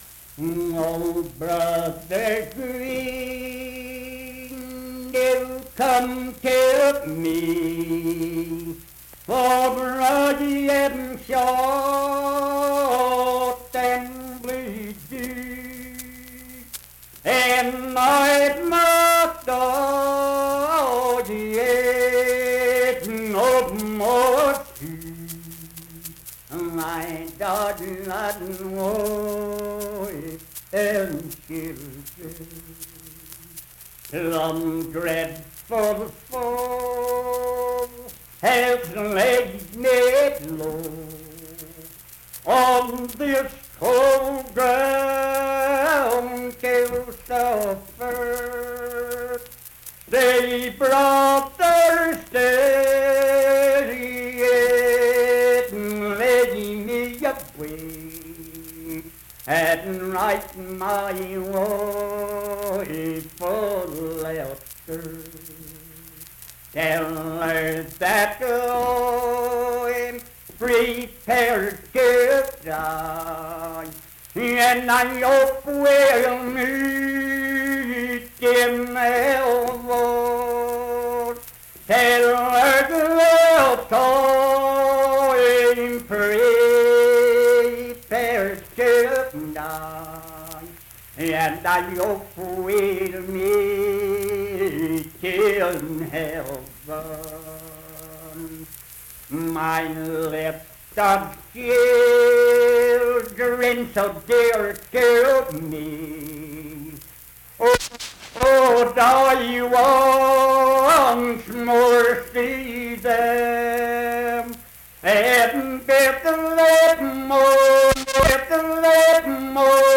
Unaccompanied vocal music performance
Voice (sung)